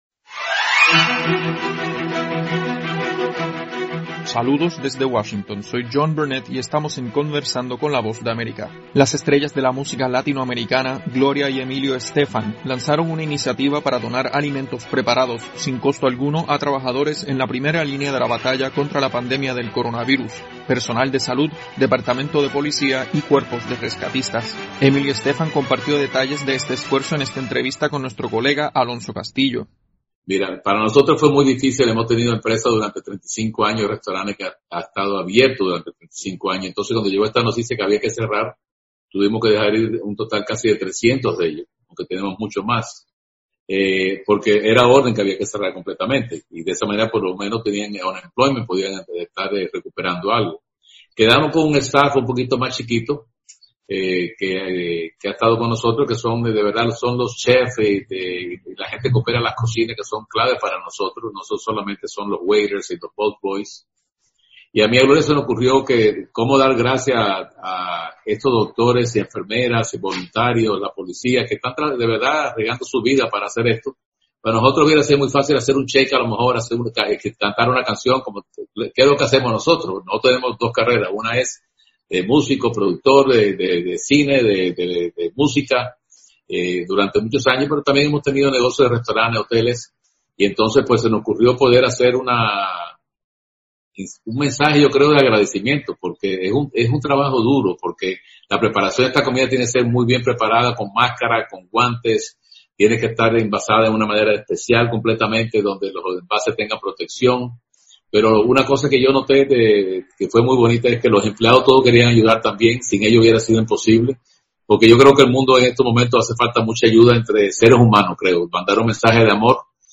Conversamos con el productor musical Emilio Estefan.